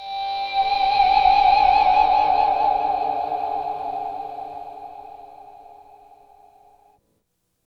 Index of /90_sSampleCDs/Roland L-CD701/GTR_GTR FX/GTR_E.Guitar FX
GTR CHUCK0BR.wav